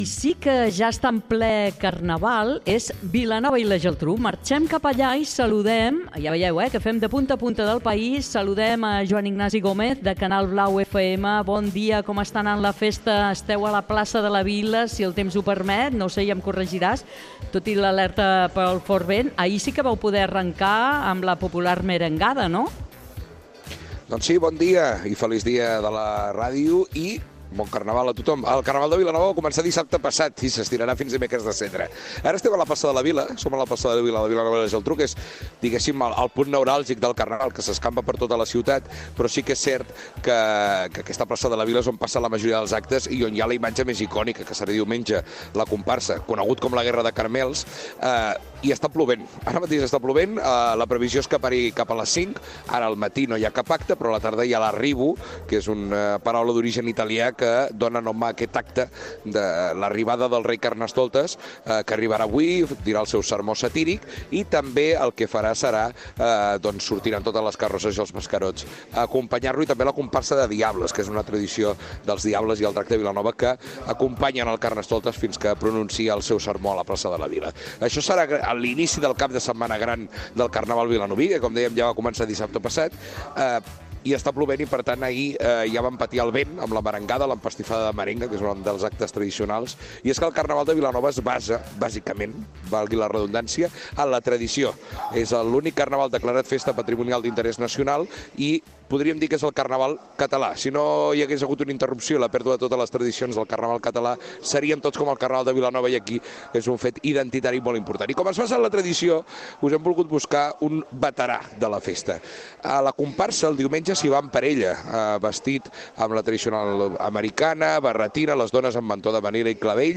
Gènere radiofònic
Entreteniment